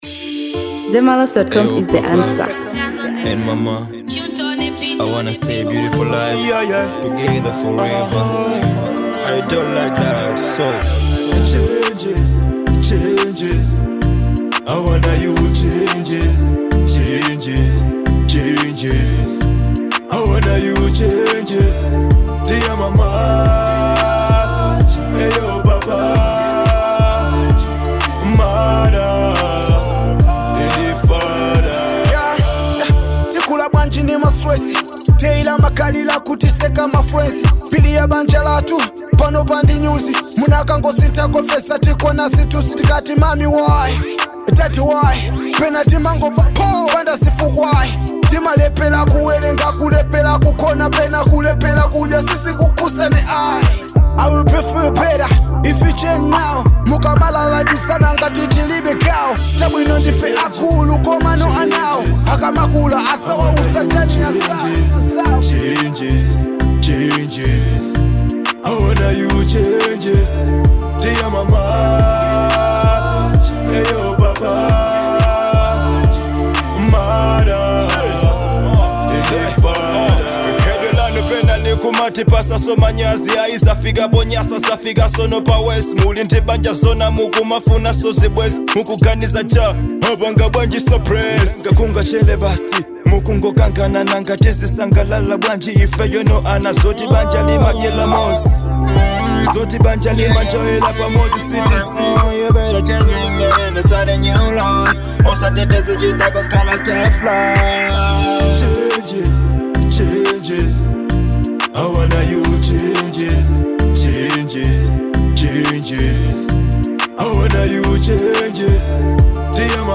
Kwaito|Afrobeats|Amapiano|Dancehall • 2025-07-12